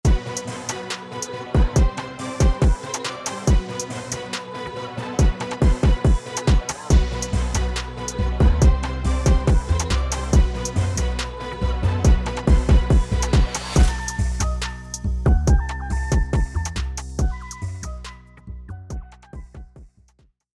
ヒップホップ×オーケストラ